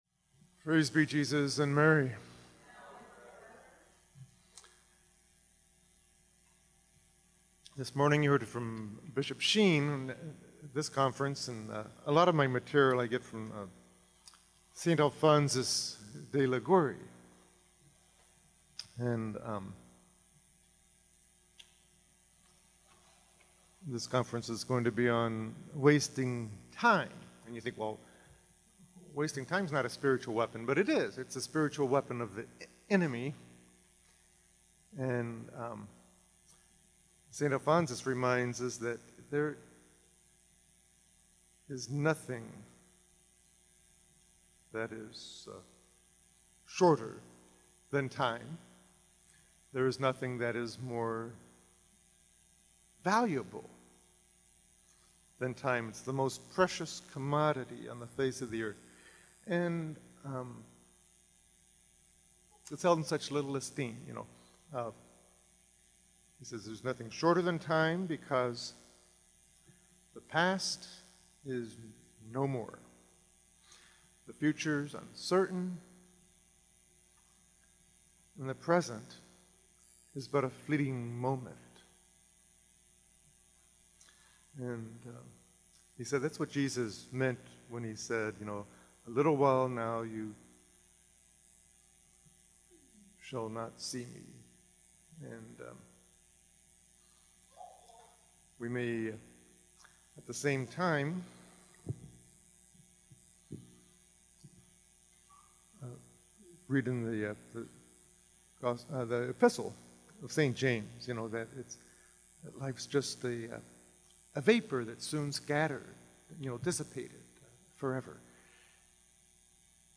This series of talks is from the 2019 annual MIM Retreat held each fall in Bloomington, Indiana on September 27-29